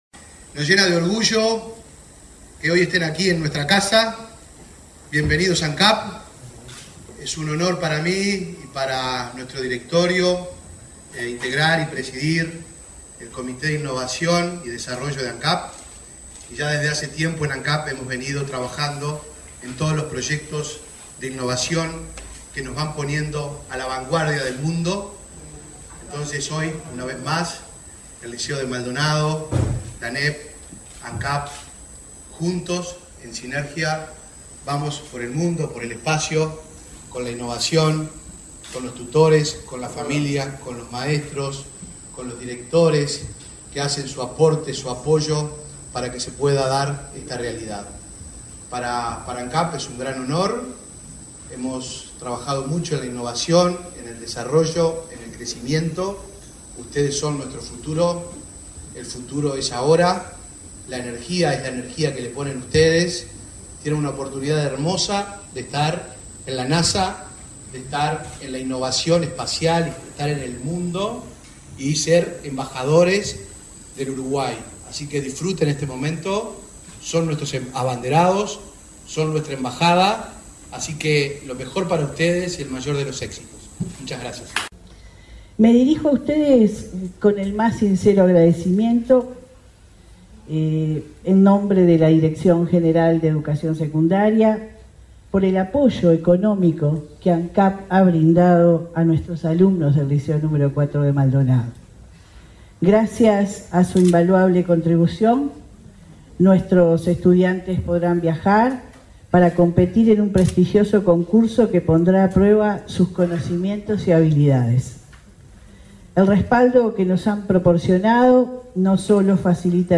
Palabras de autoridades en acto de ANEP y Ancap
Palabras de autoridades en acto de ANEP y Ancap 23/07/2024 Compartir Facebook X Copiar enlace WhatsApp LinkedIn El vicepresidente de la Administración Nacional de Combustibles, Alcohol y Portland (Ancap), Diego Durand; la directora general de Educación Secundaria, Jenifer Cherro; el presidente de Ancap, Alejandro Stipanicic, y la titular de la Administración Nacional de Educación Pública (ANEP), Virginia Cáceres, participaron del acto en el que estudiantes de Maldonado presentaron un proyecto que expondrán en la NASA.